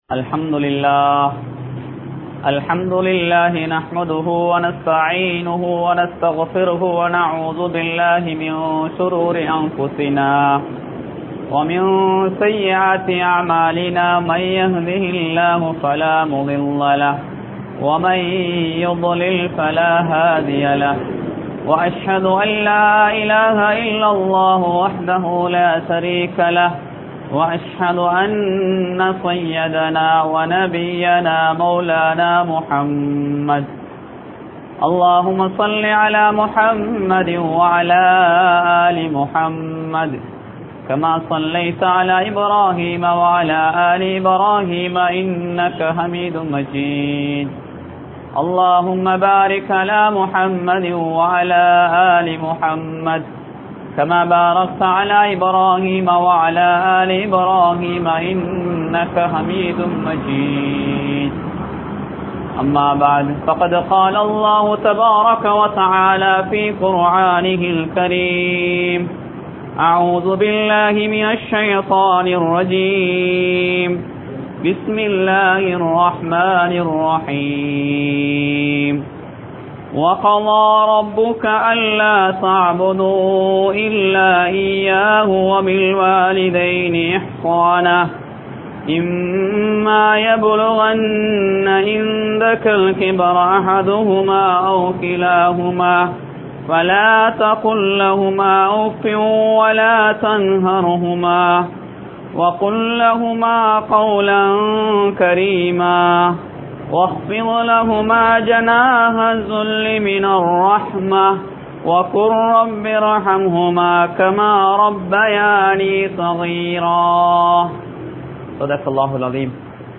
Pettroarhalai Maranthu Vidatheerhal (பெற்றோர்களை மறந்து விடாதீர்கள்) | Audio Bayans | All Ceylon Muslim Youth Community | Addalaichenai